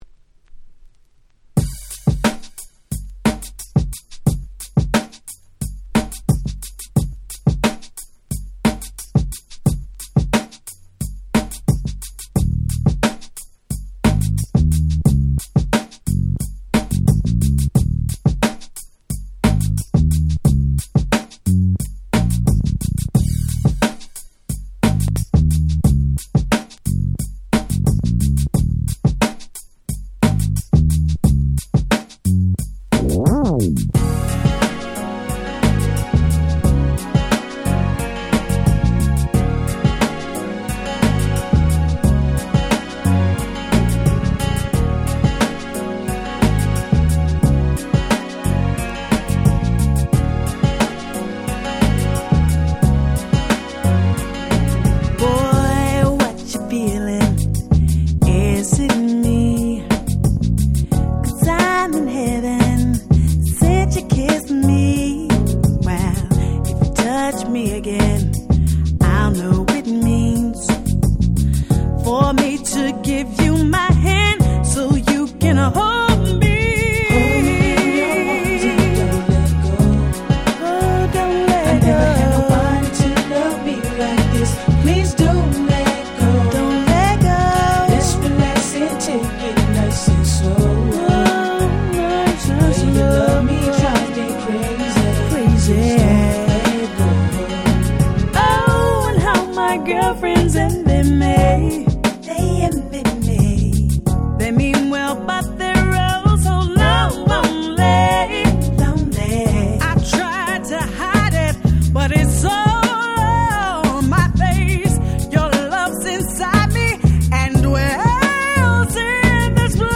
98' Very Nice R&B / Slow Jam !!